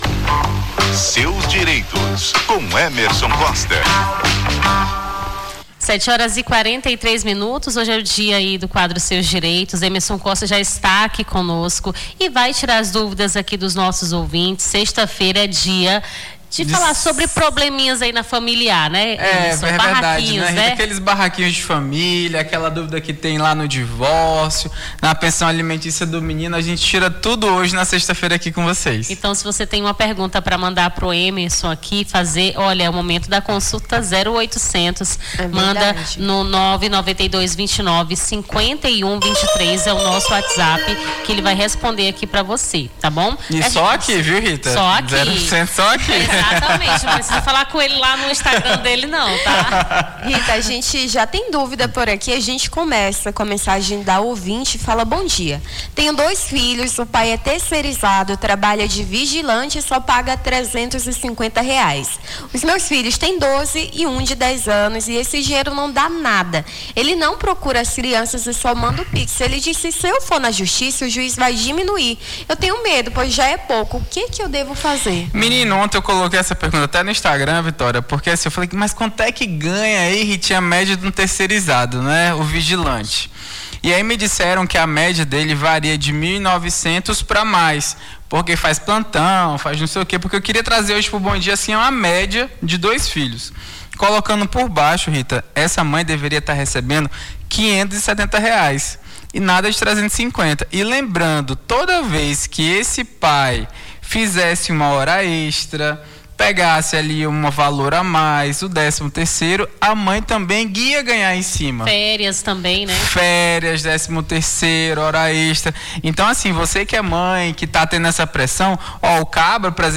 Seus Direitos: advogado tira dúvidas sobre direitos de família